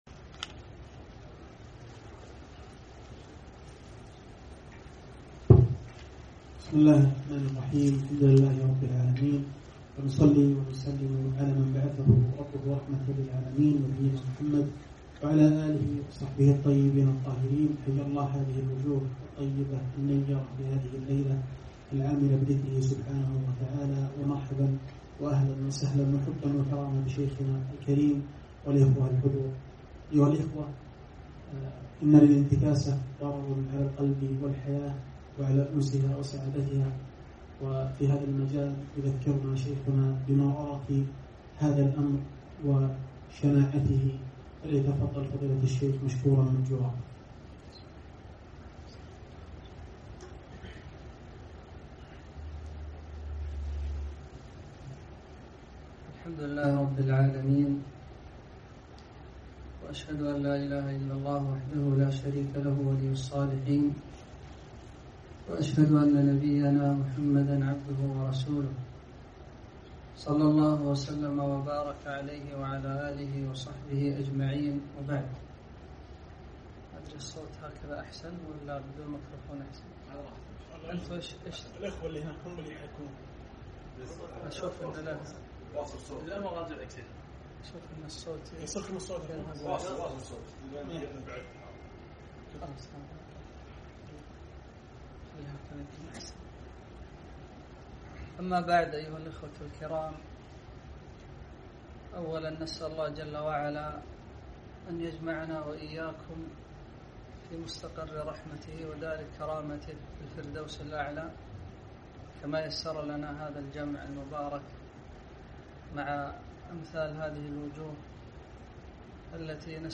محاضرة - مرارة الإنتكاسة